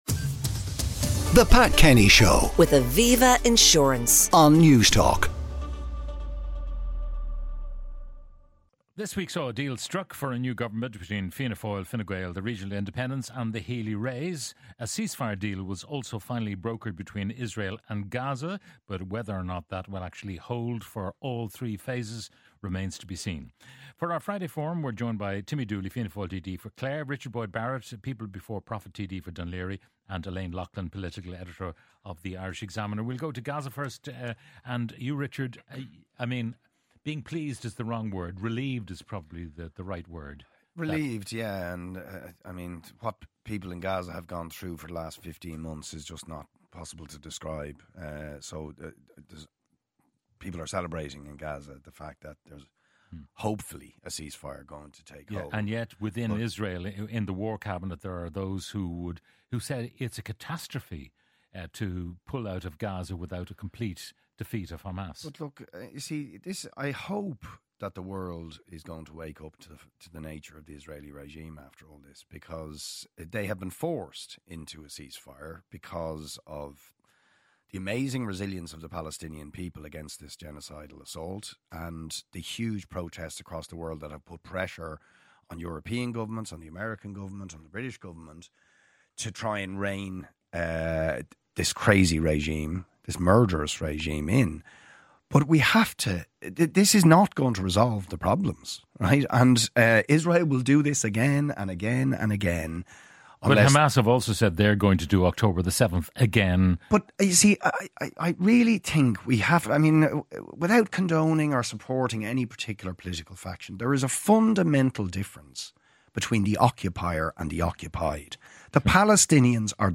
Pat brings you the sharpest analysis of news and current affairs on the radio and fresh perspectives on the issues that will define a generation. Breaking news is interwoven with reflective news features and reports from a variety of reporters based across the country.